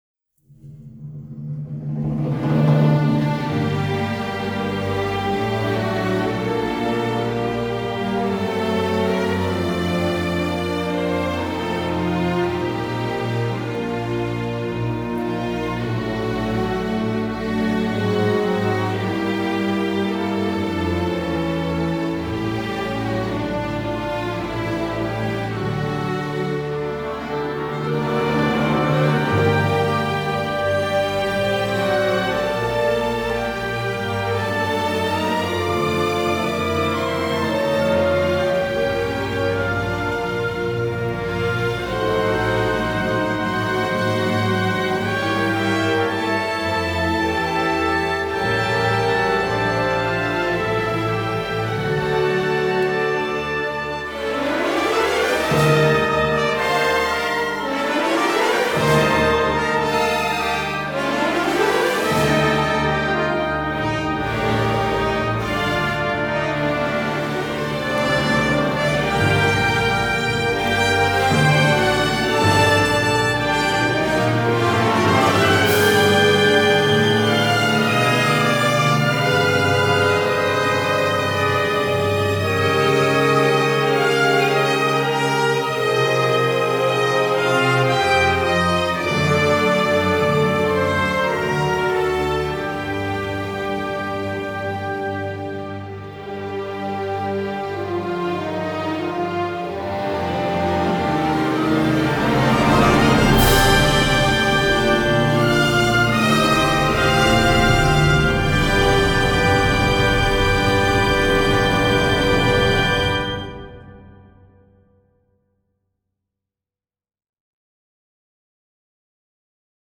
Versión Sinfónica